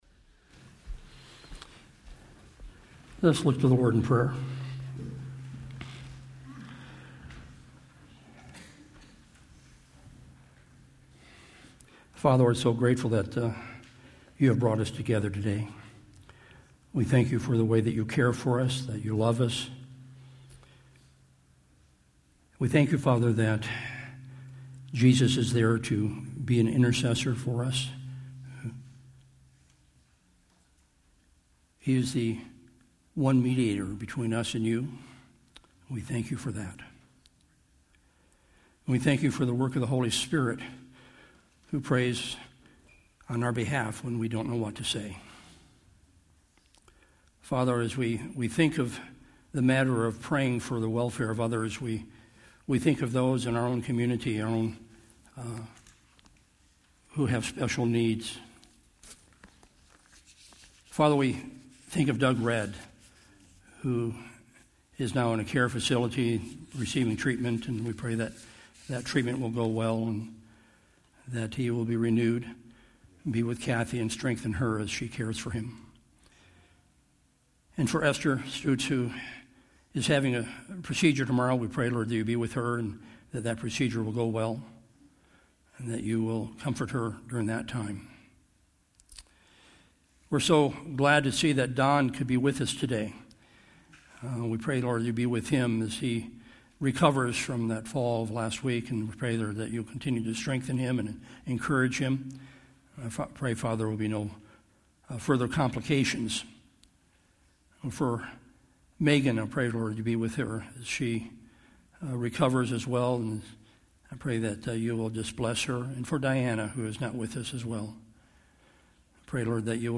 Sermons | Solid Rock Christian Fellowship